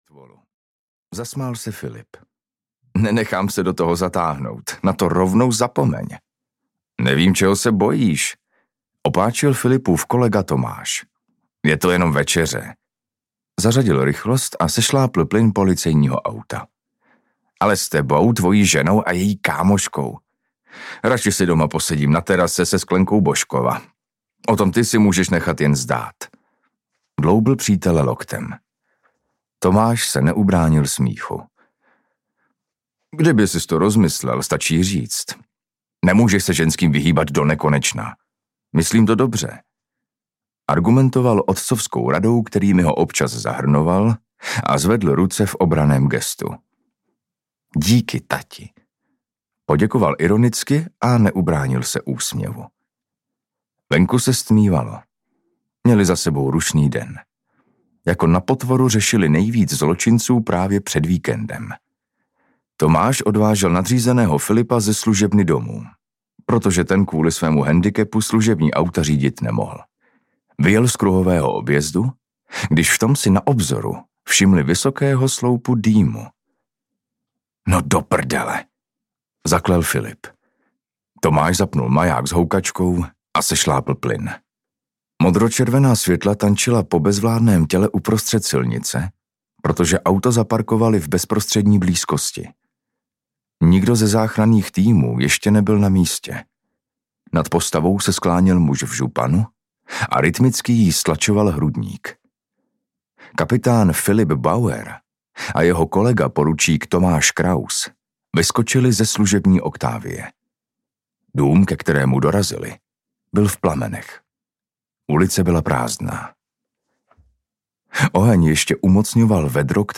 Hladina krve audiokniha
Ukázka z knihy